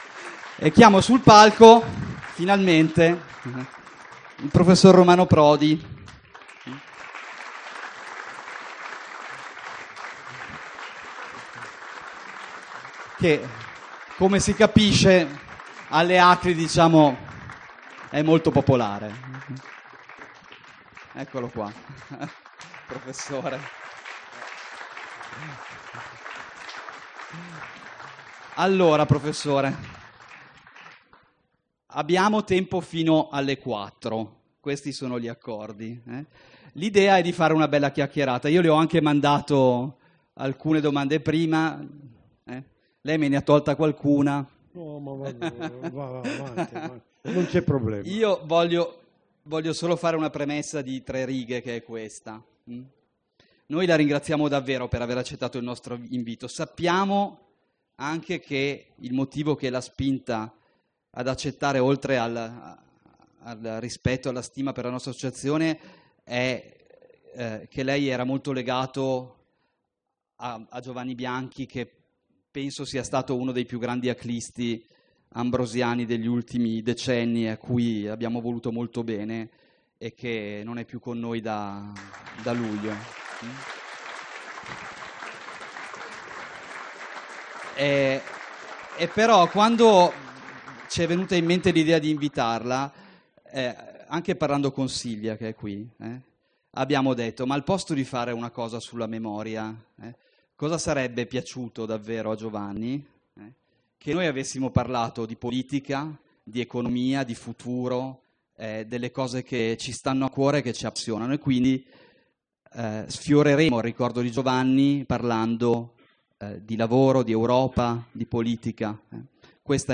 Milano, Piazza Santo Stefano 29 settembre 2017, Spazio Convegni dell’eVento ACLI, file audio mp3.